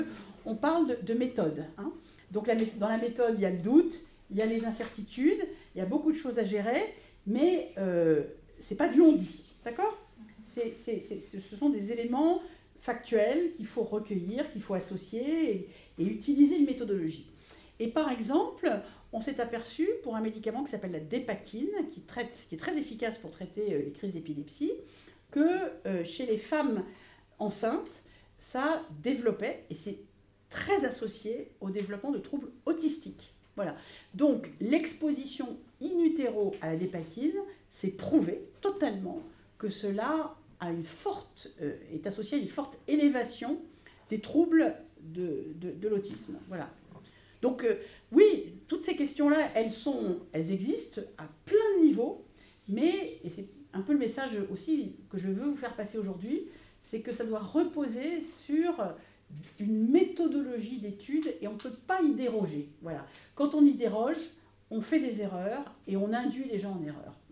Irène Frachon intervient en classe de T STL
Irène Frachon est intervenue le mardi 2 avril en classe de terminale STL. Elle a présenté l’affaire du Médiator et a répondu aux questions des élèves.